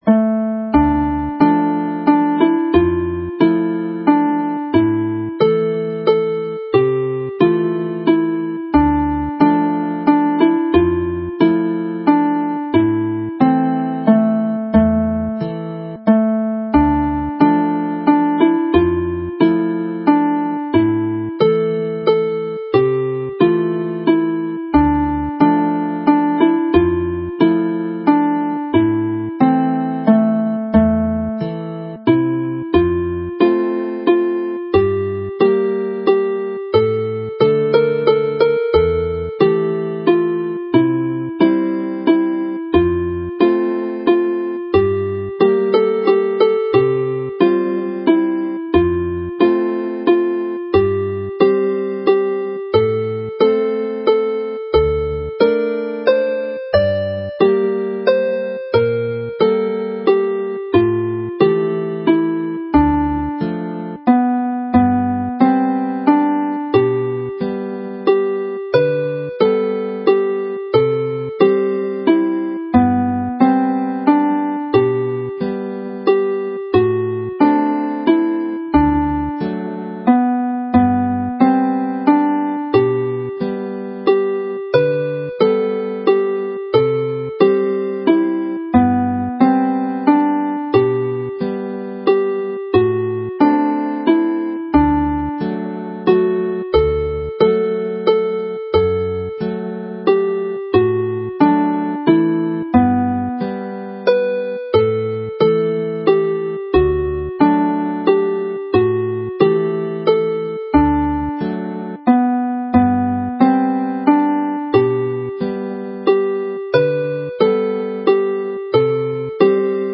Play the set slowly
This set comprises two traditional Welsh tunes and a more recent one.